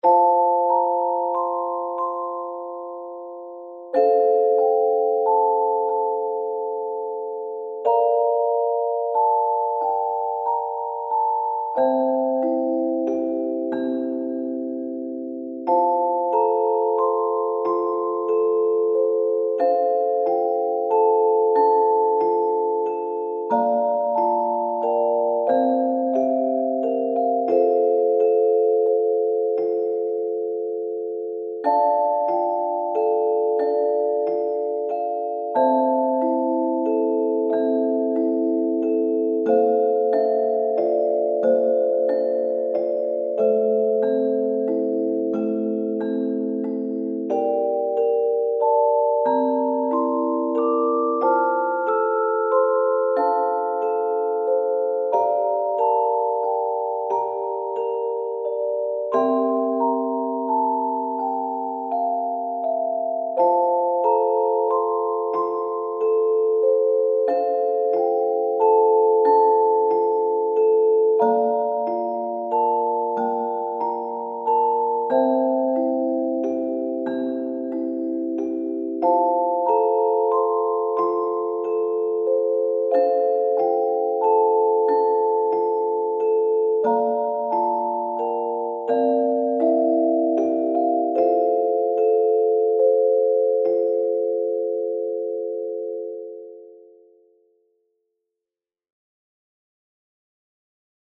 今日のテーマは私の大好きな楽器「チェレスタ」です。チェレスタはオルゴールの響きに似た音を出す鍵盤楽器です。とても可愛らしく天使のような音色でよく使う楽器の一つです。おやすみ前の癒しのひと時にどうぞ。
0401チェレスタ.mp3